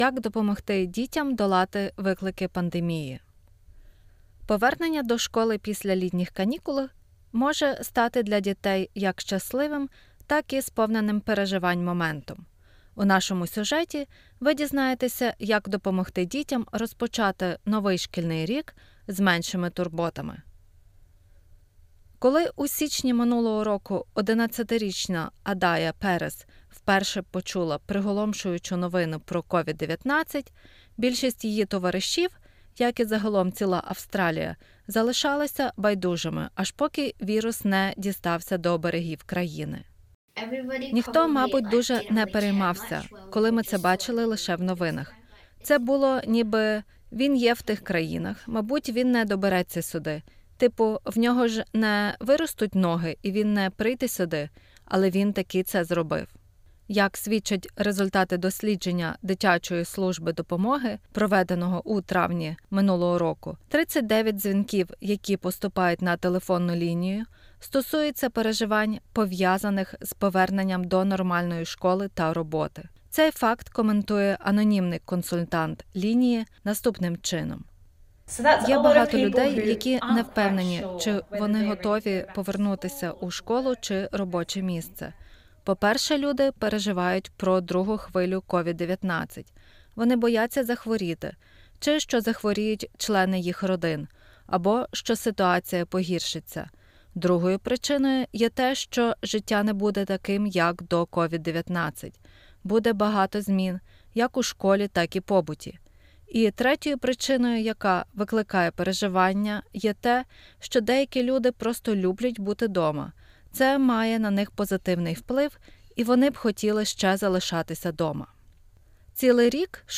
Повернення до школи після літніх канікул може стати для дітей як щасливим, так і сповненим переживань моментом. У нашій радіорозповіді ви дізнаєтеся як можна допомогти дітям розпочати новий шкільний рік з меншими турботами.